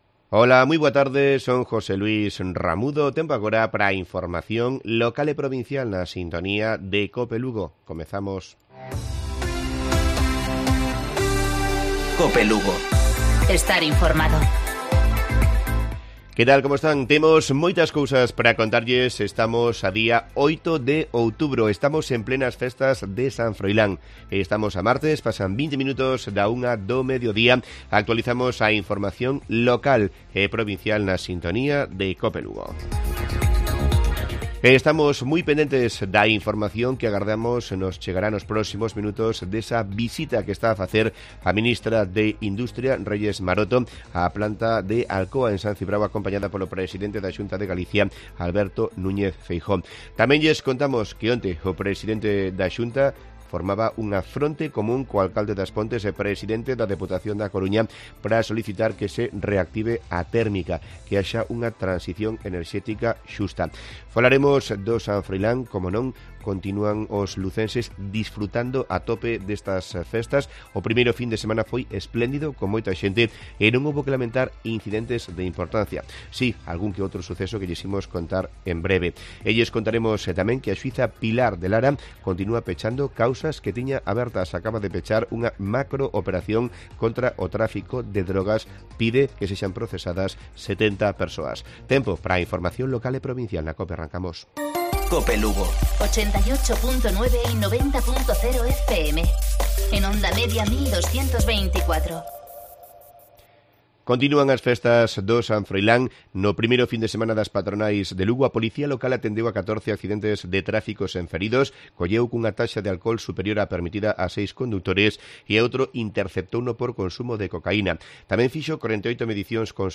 Informativo Mediodía Cope Lugo. 13:20-13:30 horas